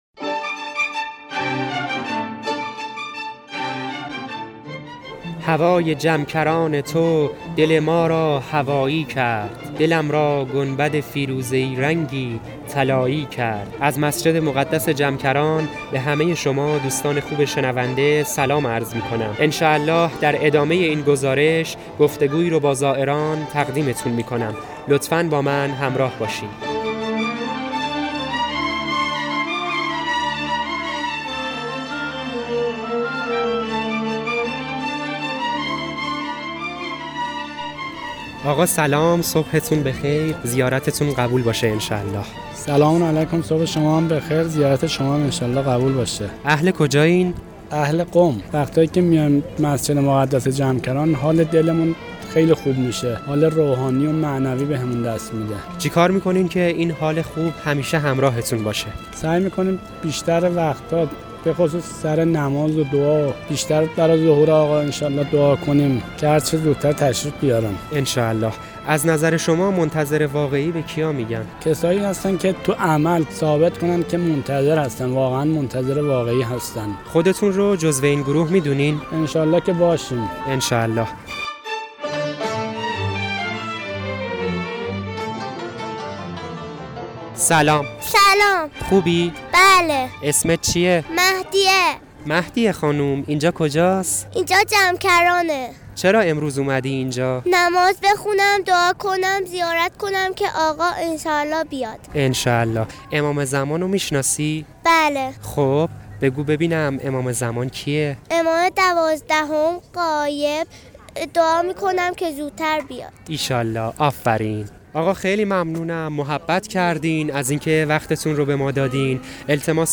پادکست گزارشی از مسجد مقدس جمکران و گفت و گو با زائران و مجاوران درباره مهدویت و انتظار